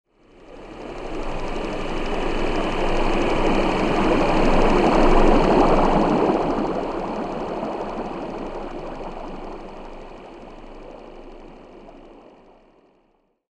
Звук движущейся торпеды с винтом